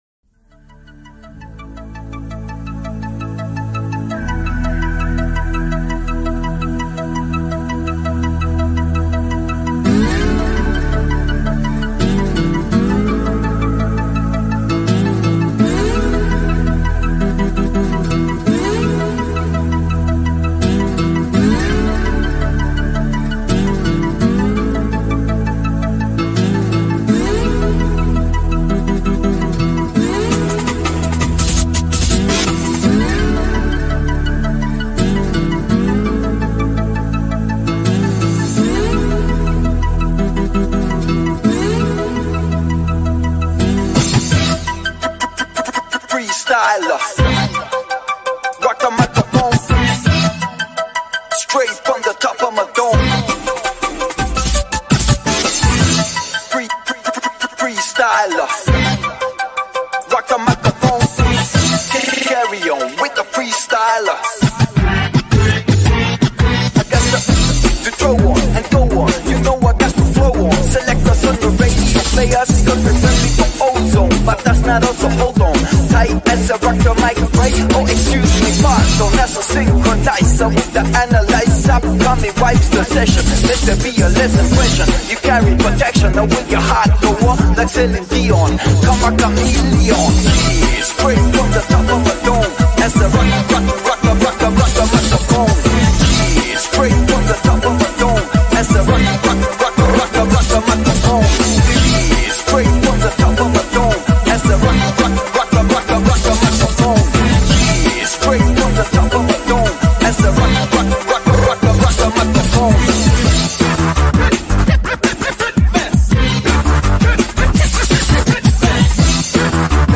Easyjet A319 Landinv At Milan Sound Effects Free Download